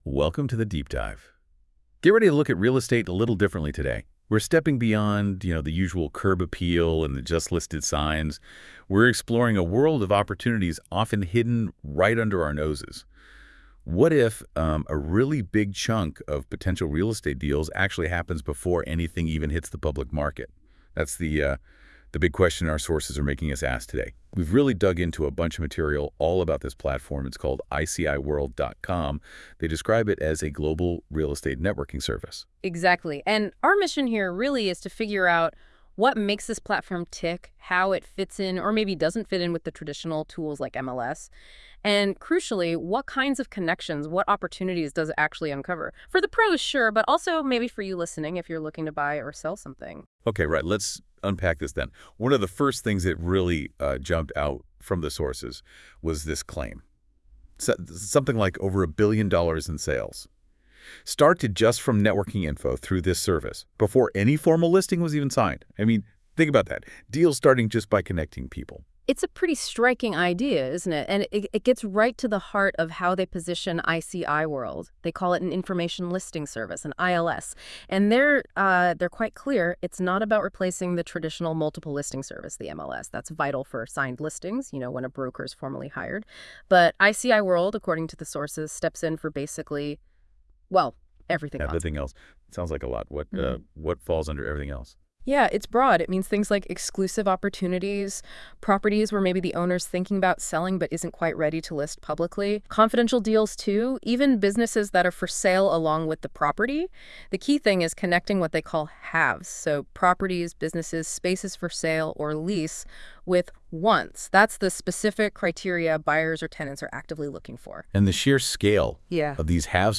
AI Voice Review of ICIWorld